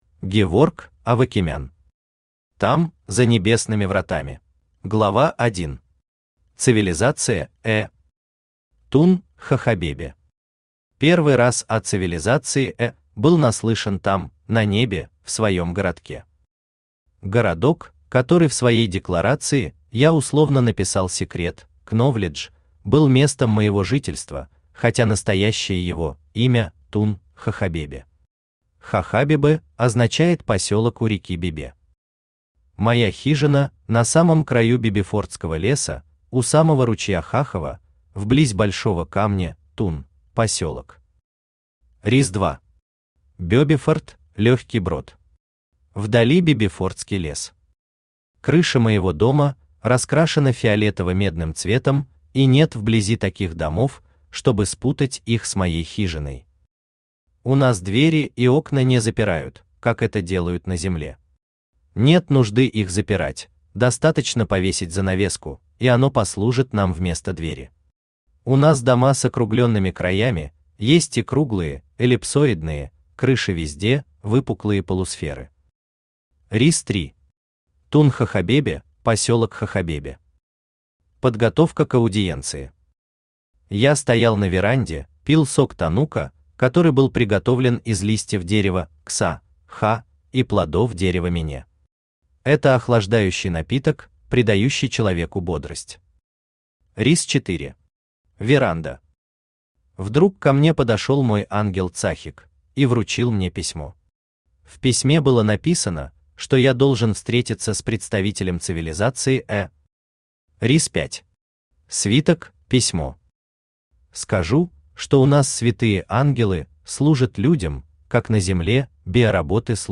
Аудиокнига Там, за небесными вратами…
Автор Геворк Александрович Овакимян Читает аудиокнигу Авточтец ЛитРес.